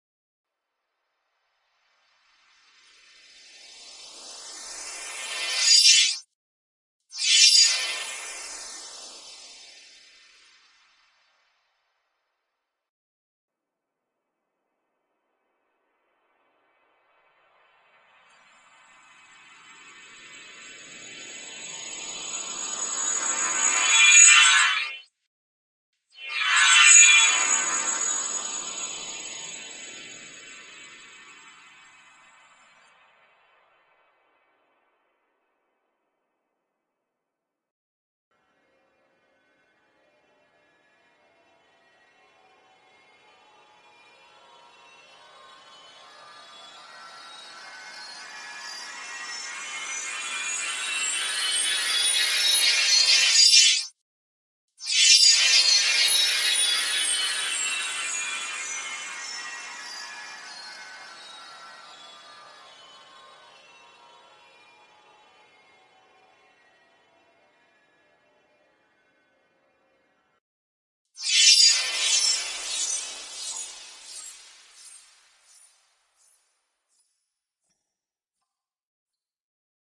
描述：魔杖魔法/成长/回溯效果
Tag: 成长 改造 扭转 旋转 童话 定型 记忆闪回 叮当声 陈词滥调 咒语 魔棒 媚俗 小叮当 魔杖 魔法 向后 风铃